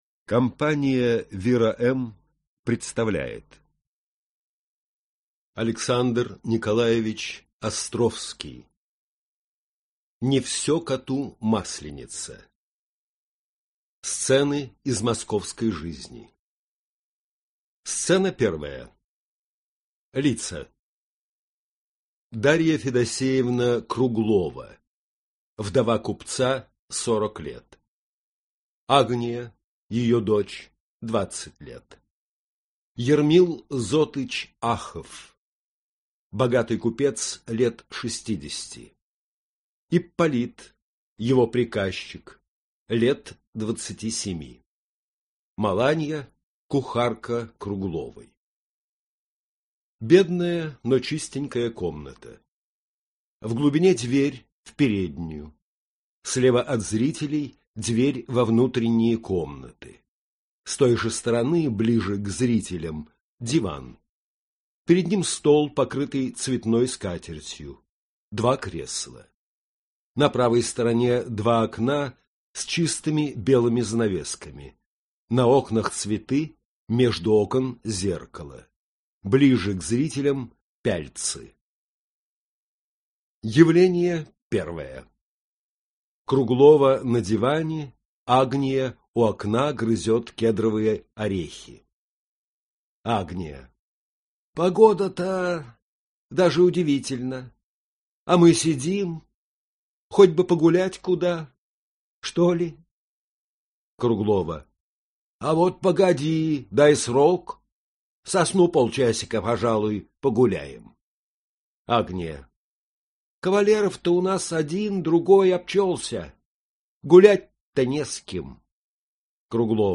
Аудиокнига Не все коту масленица. Пьеса | Библиотека аудиокниг